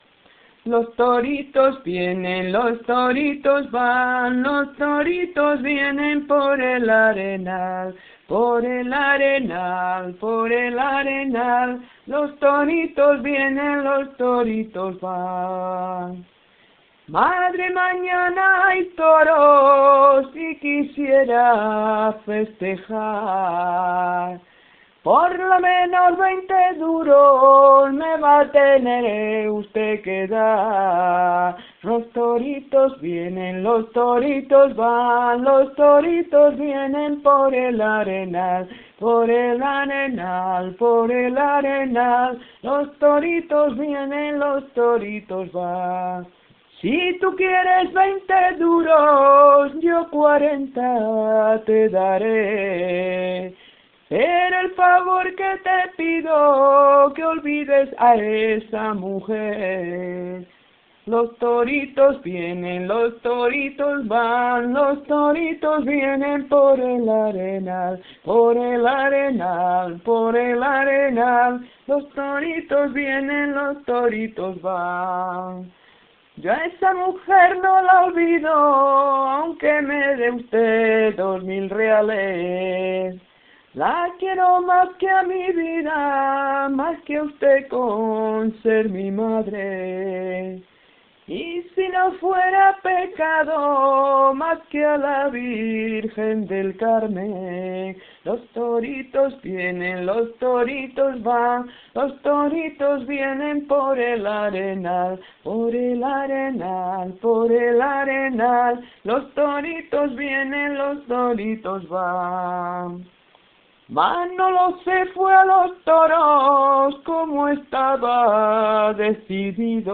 Escuchar canción cantada
Informantes: cantores anónimos.
Lugar: Navalmoral de la Mata.